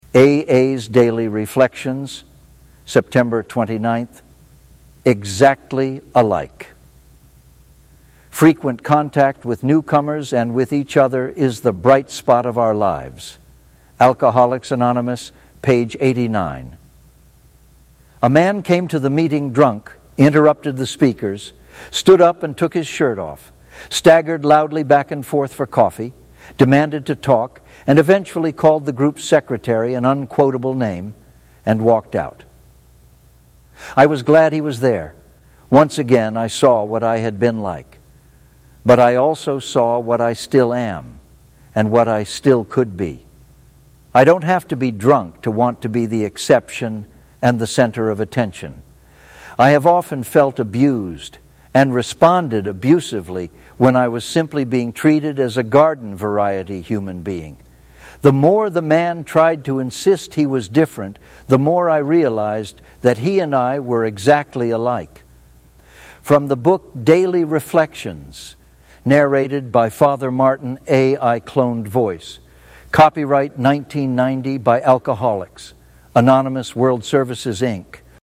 A.I. Cloned Voice